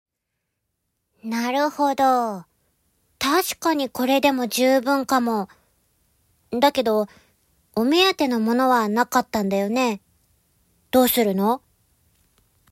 ボイスサンプル
セリフB